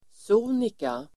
Ladda ner uttalet
sonika adverb, Uttal: [s'o:nika] Idiom: helt sonika ("helt enkelt") (quite simply)